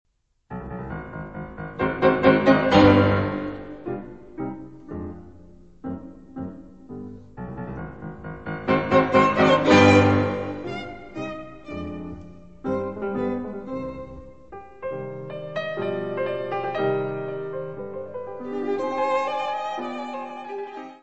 : stereo; 12 cm + folheto
violino
Music Category/Genre:  Classical Music
Sonatas for violin and piano